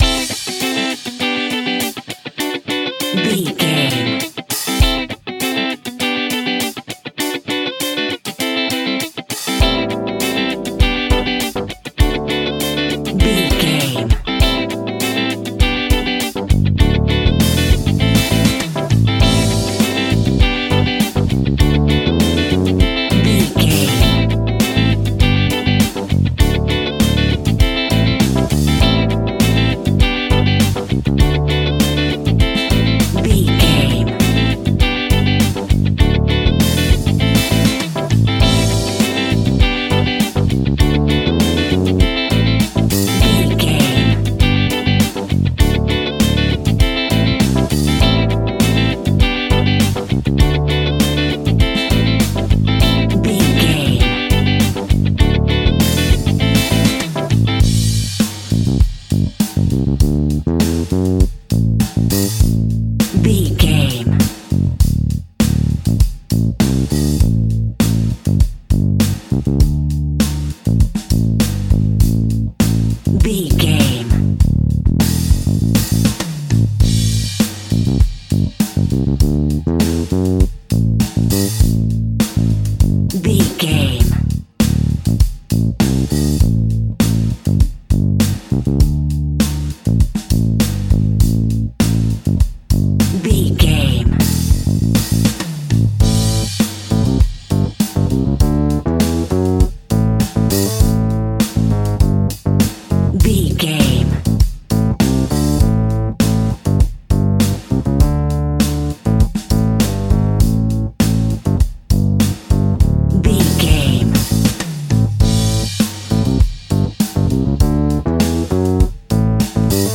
Uplifting
Aeolian/Minor
pop rock
indie pop
fun
energetic
cheesy
acoustic guitars
drums
bass guitar
electric guitar
piano
electric piano
organ